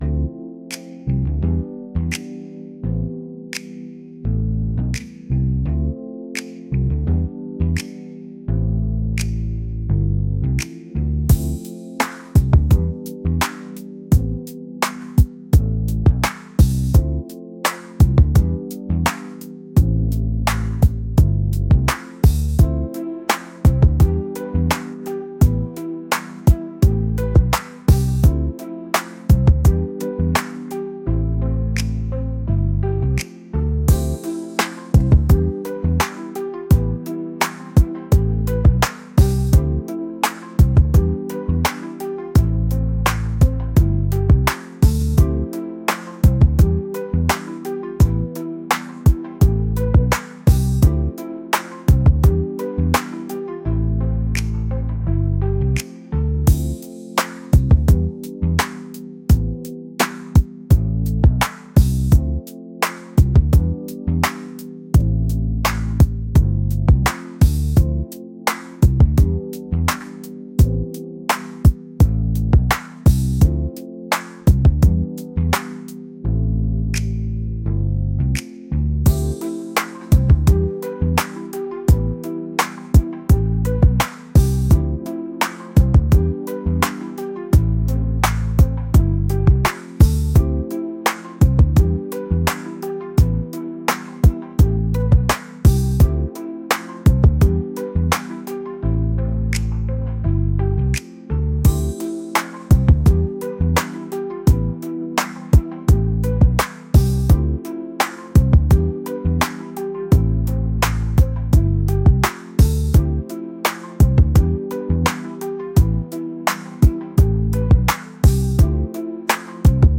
romantic | smooth | soul | groovy